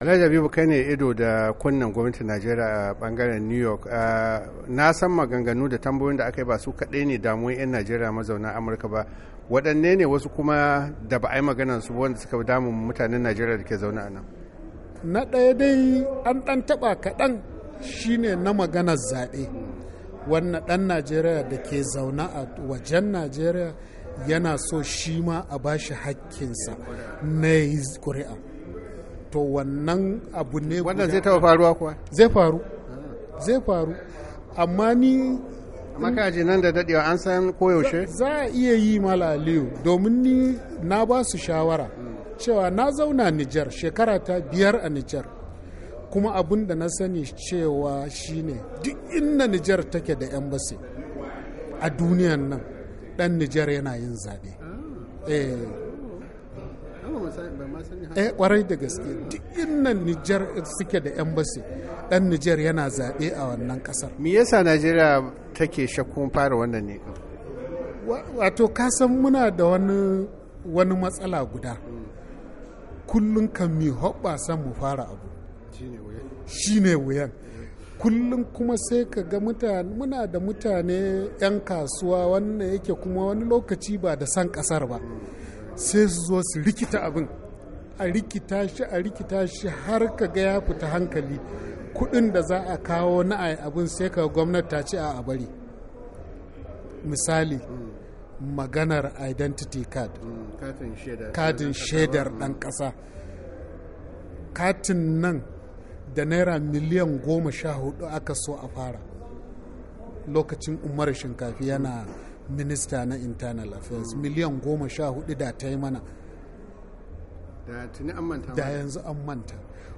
Yayin da yake zantawa da wakilin Muryar Amurka Mataimakin Jakadan Najeriya a Amurka mai kula da yankin New York yace kwace jama'a daga sarakunan gargajiya ya haddasa rashin tsaro domin basu san mutanen dake yankunansu ba.